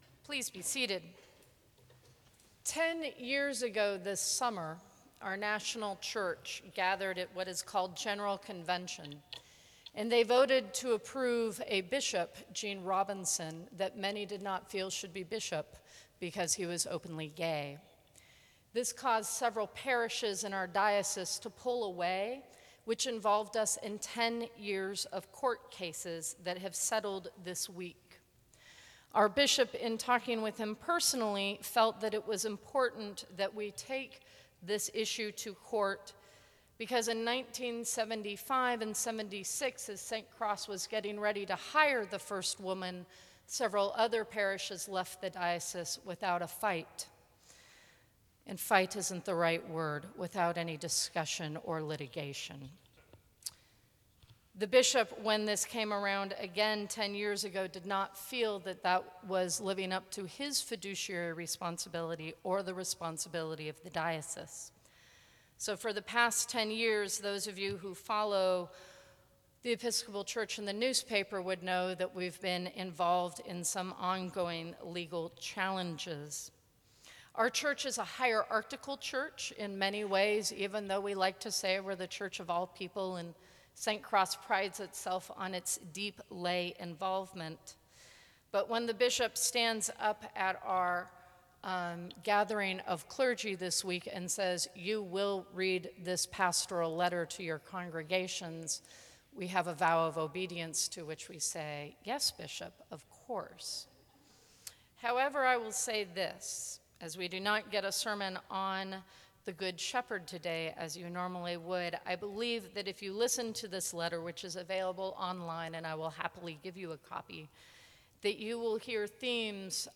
Sermons from St. Cross Episcopal Church
Right Rev. J. Jon Bruno, Bishop of the Diocese of Los Angeles, issued a pastoral letter to be read to all congregations in the diocese.